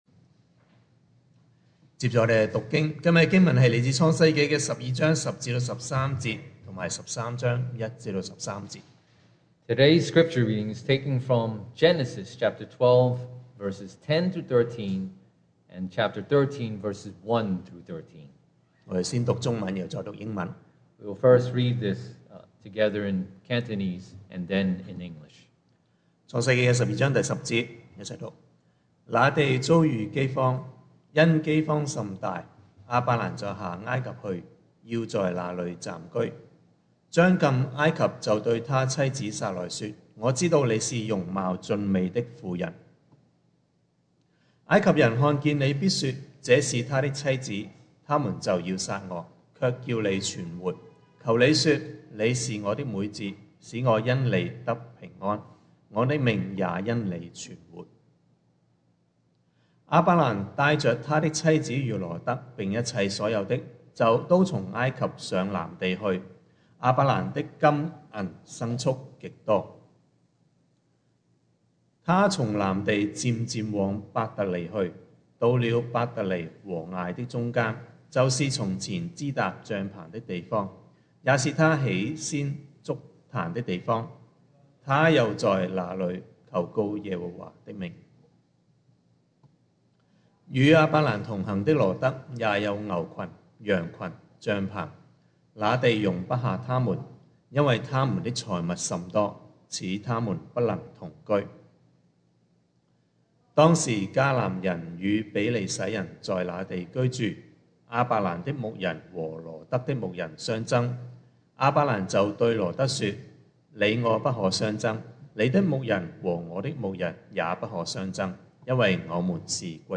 Series: 2021 sermon audios
Service Type: Sunday Morning